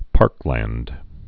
(pärklănd)